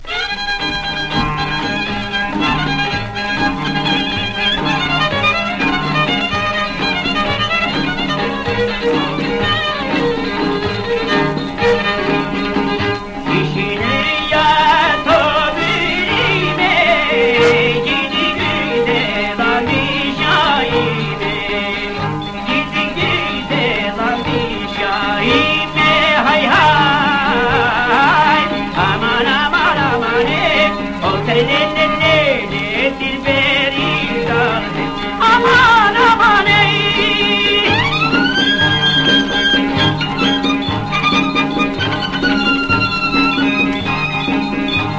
World　USA　12inchレコード　33rpm　Mono